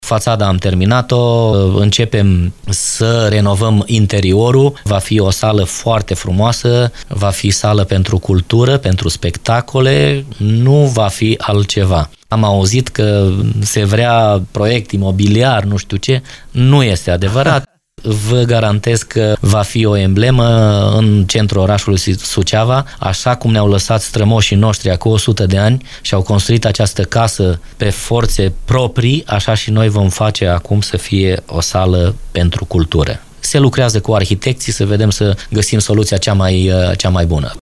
Deputatul Uniunii Polonezilor din România GHERVAZEN LONGHER a precizat că fațada clădirii a fost renovată, de acum urmând să se lucreze la interioare.
El a negat, în exclusivitate la postul nostru de radio, că în zonă ar urma să se dezvolte un proiect imobiliar.